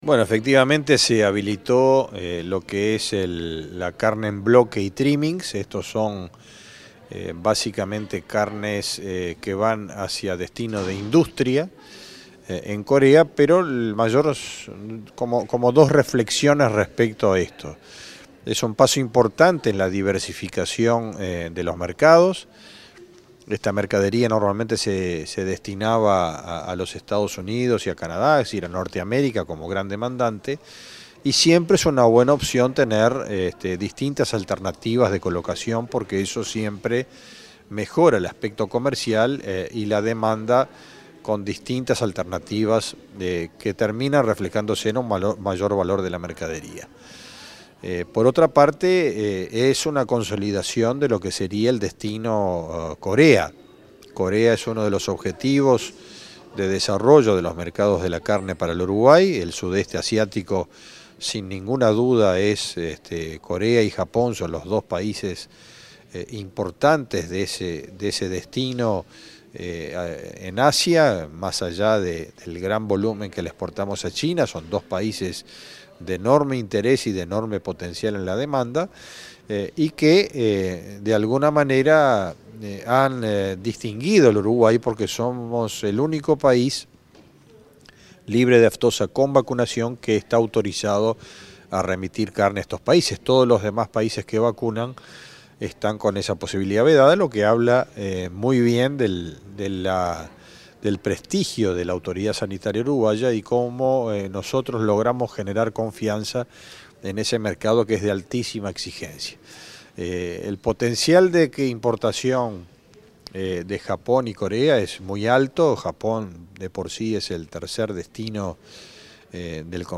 Declaraciones del ministro Ganadería, Agricultura y Pesca, Fernando Mattos